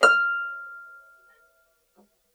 interactive-fretboard / samples / harp / F6.wav
F6.wav